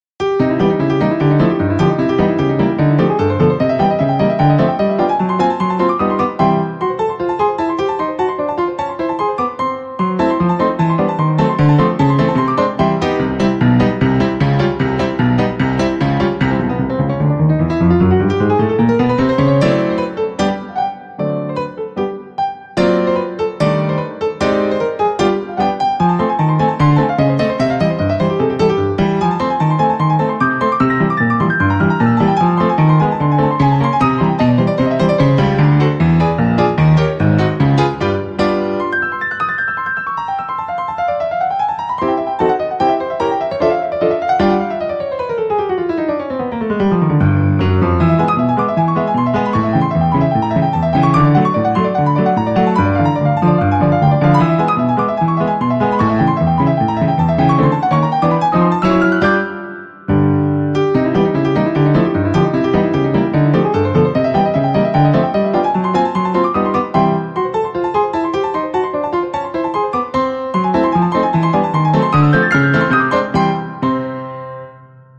元気が出るラグタイムピアノです。